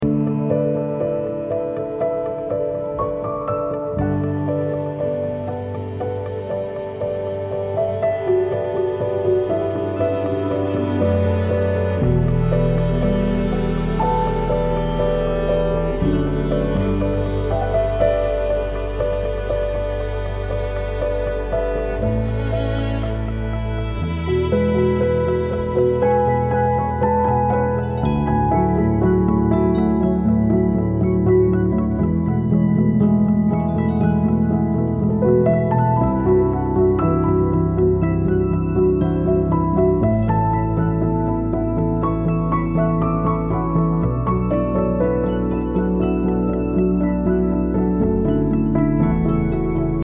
Post Classical >